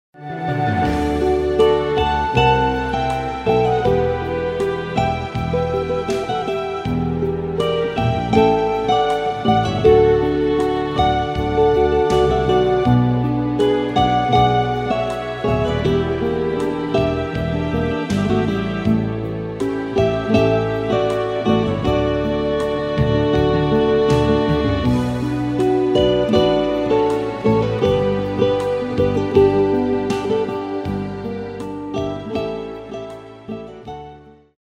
- Pieces for guitar duo -